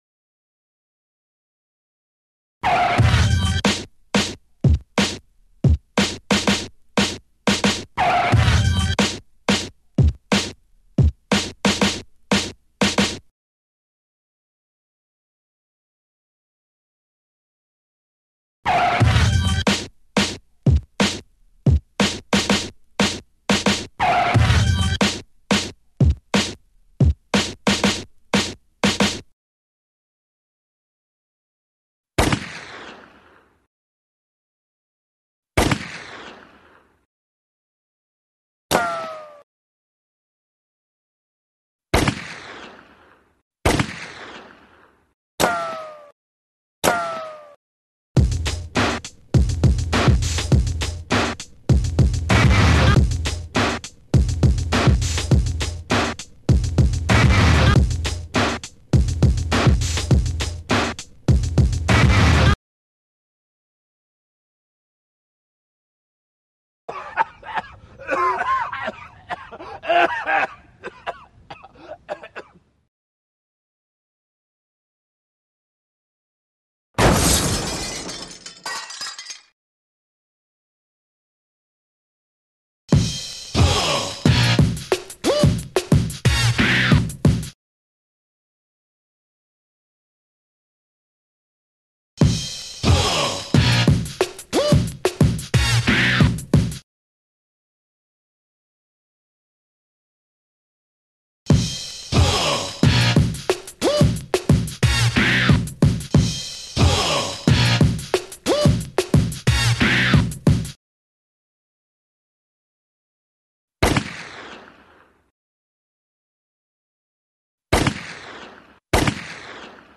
This project is a guerilla performance/audio piece that utilizes 3 bike-borne mobile sound systems playing back precomposed sound collages produced by myself.
The goal of the piece is to play with notions of accepted and expected ambient sound in a public space, and to inject surprising, and at times subversive, audio information into the consciousnesses of urban dwellers. Also, a more formal concern is one of rapidly varying spatialization of sound in an uncontrolled environment.
here is one bike's-worth of one section of the original audio.